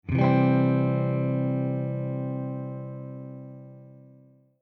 They are three tones or more played together at the same time.
C Chord
cchord.mp3